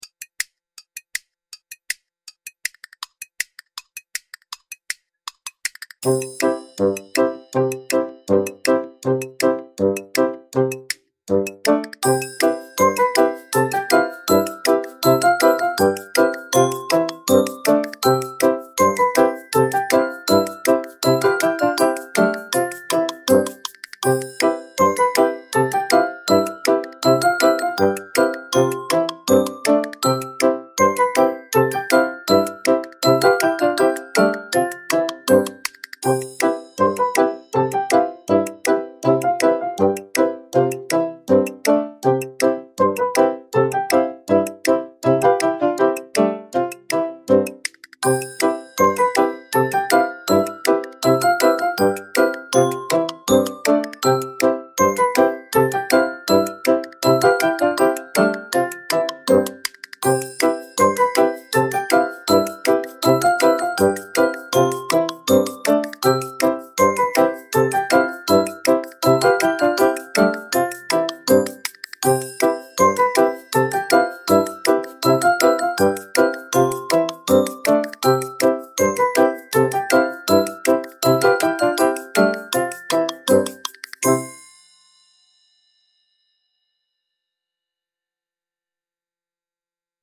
whimsical cartoon adventure music with bouncy percussion and recorder